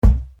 Kicks
nt kick 3.wav